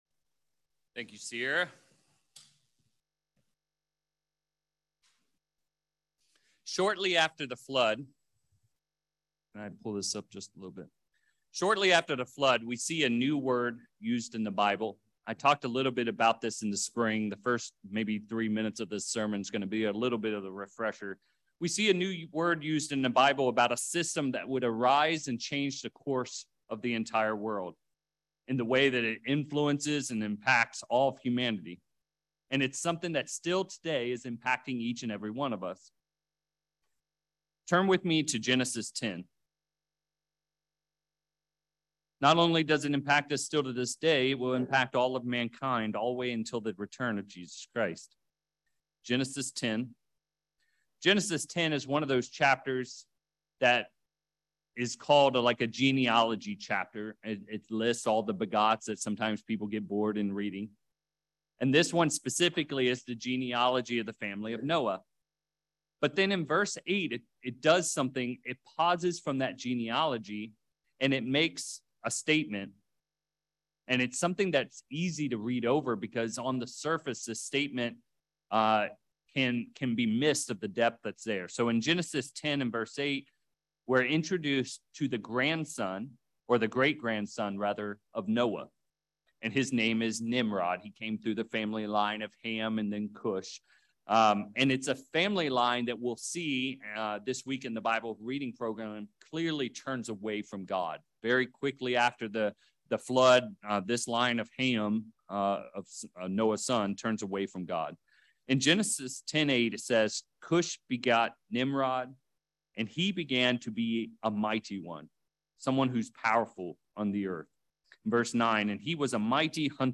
In this sermon, we will also look at the lives of four men who lived in physical Babylon and glean seven lessons that will help us as we live each day surrounded by Babylonian systems today.